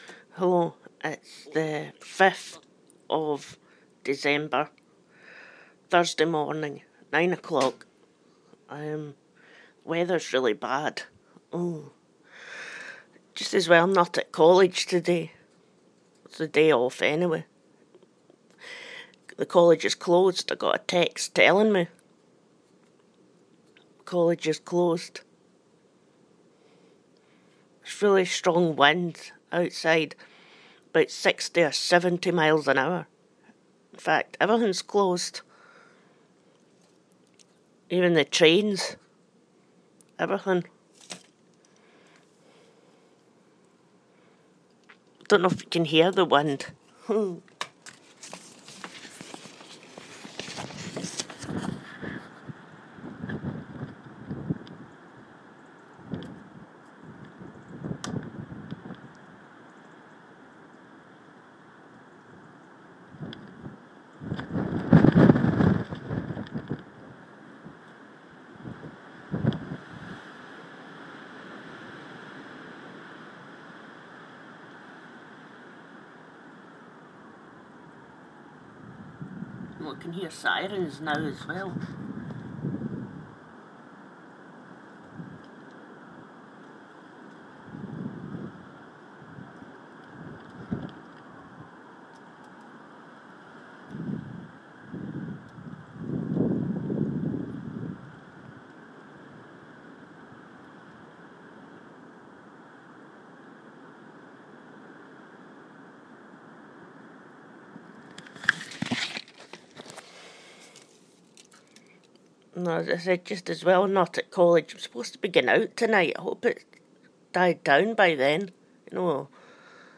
strong wind